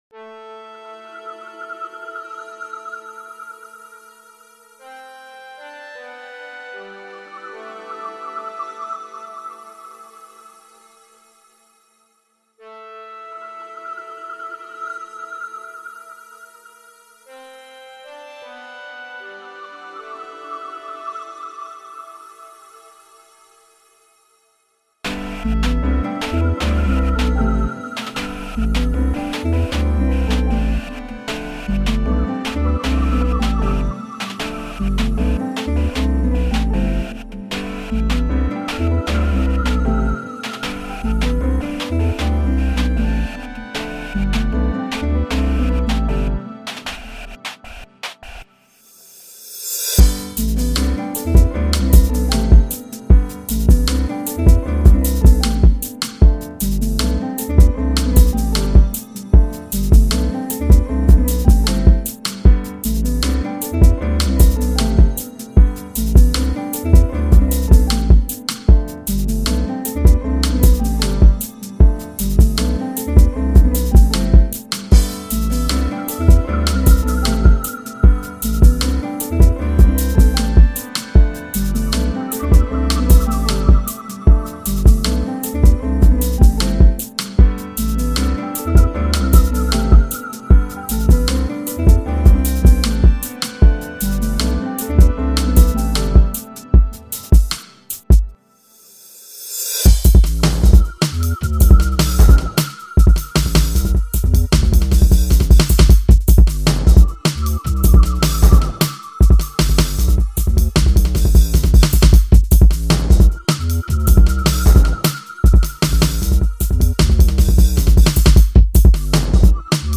Chill/Breakbeat